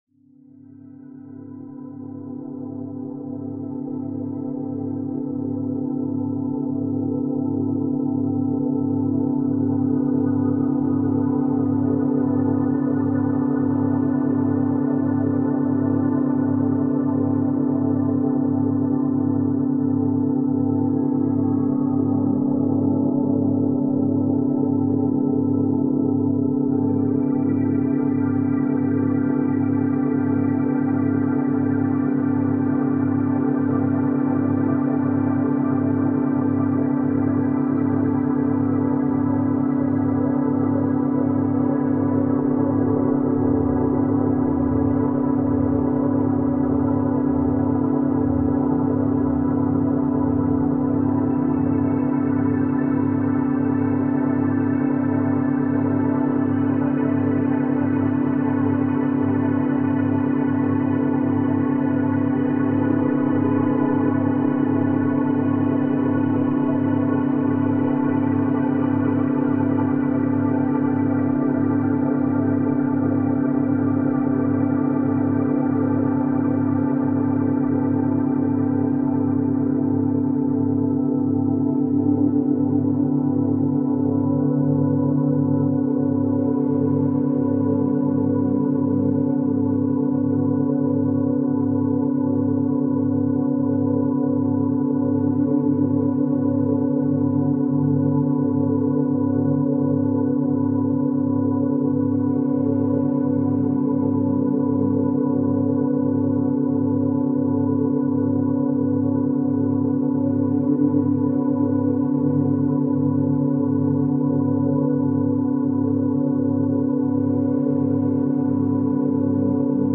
Background Sounds, Programming Soundscapes, Coding Beats